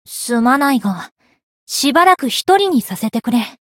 灵魂潮汐-密丝特-闲聊-不开心.ogg